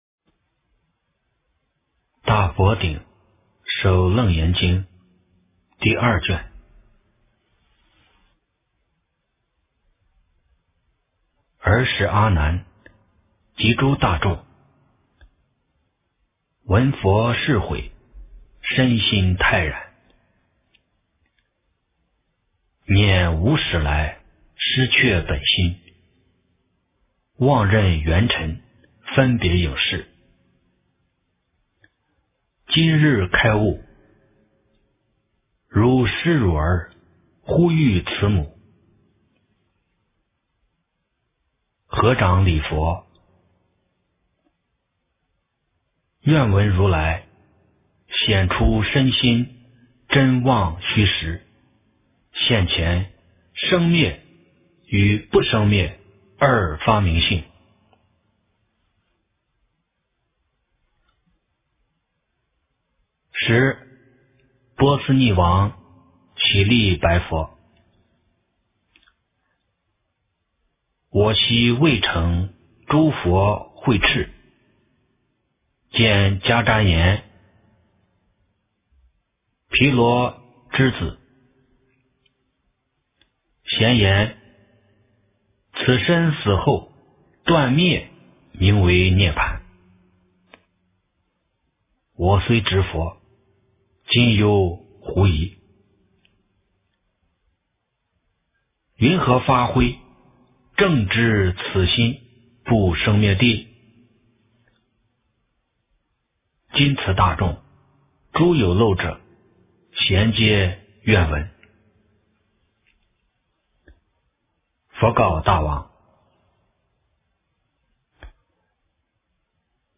楞严经第02卷（念诵）